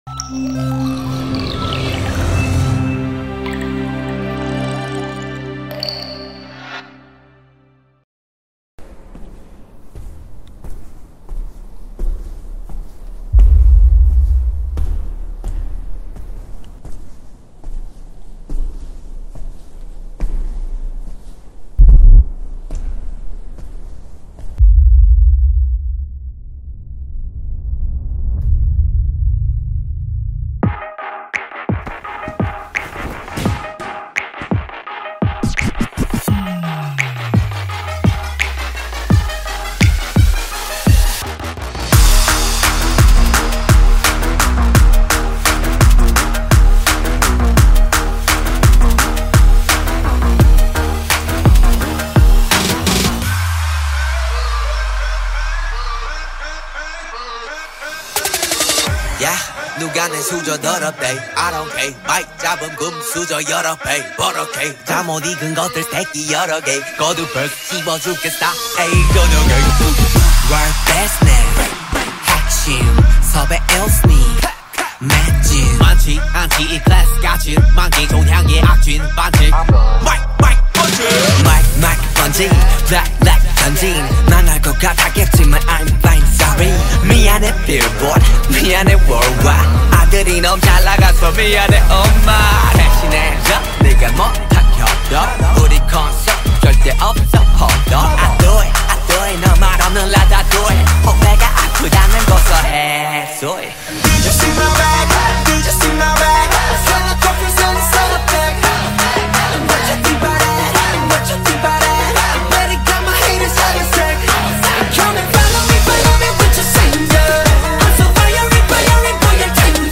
Carpeta: Baile internacional mp3